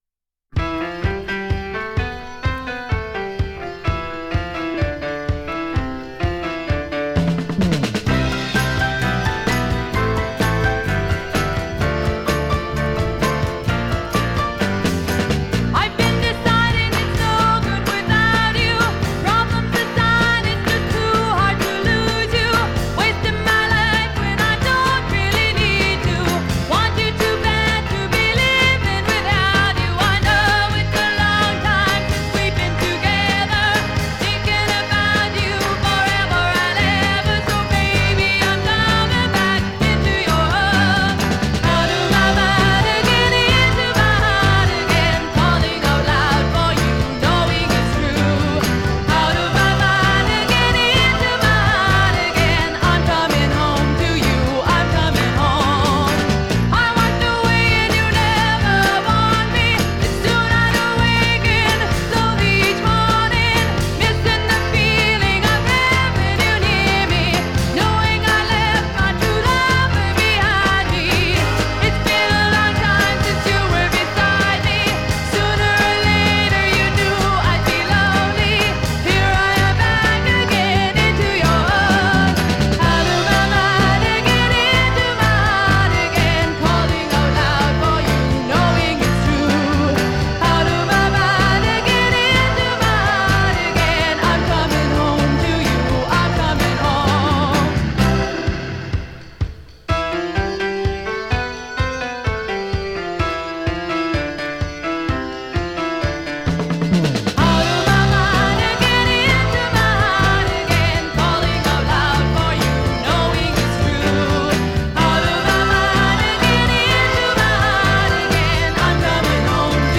Жанр: Pop Rock, Folk Rock, Funk / Soul, Vocal